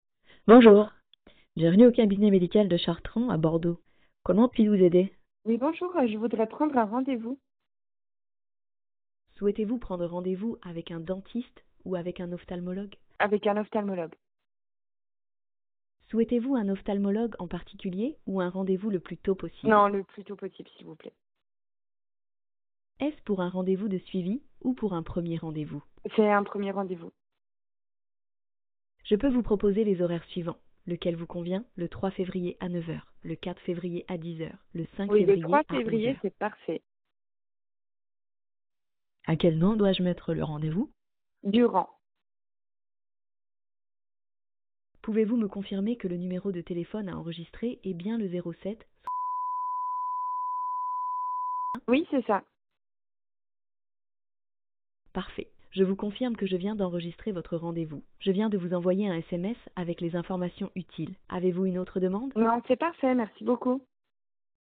Listen to our various callbot demos and try them out for yourself by dialing the numbers shown (at no extra cost).
demo-callbot-medical-dydu-jan25-1.mp3